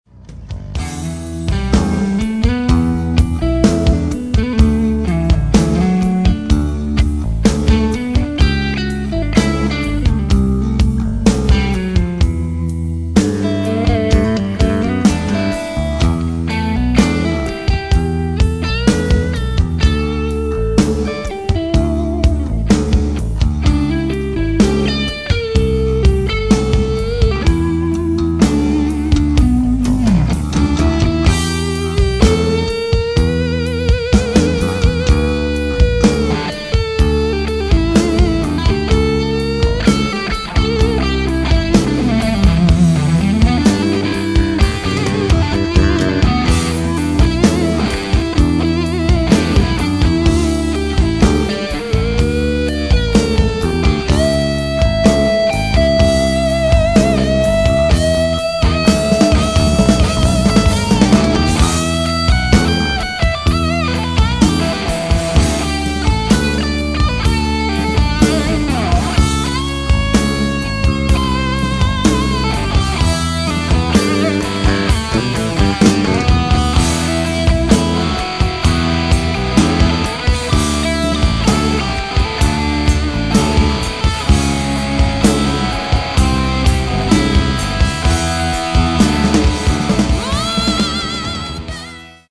Trainwreck with a 1959 Les Paul
trainwreck_with_59_les_paul.mp3